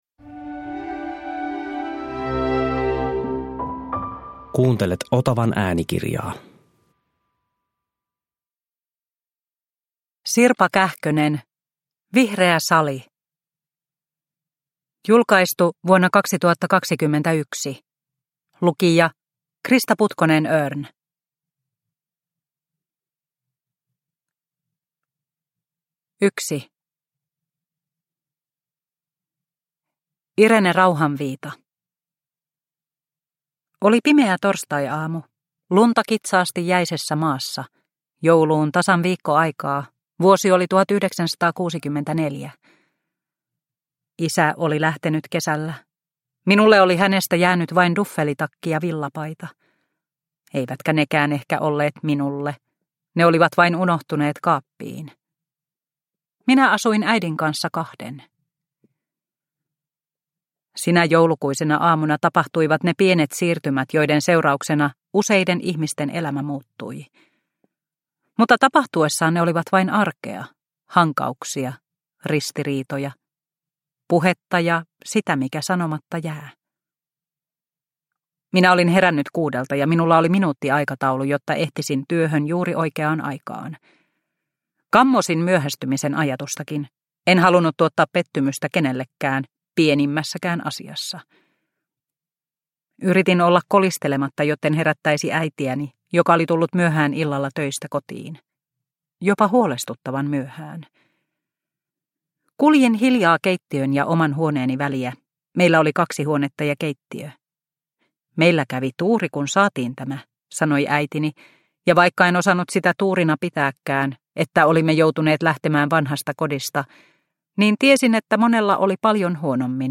Vihreä sali – Ljudbok – Laddas ner
Produkttyp: Digitala böcker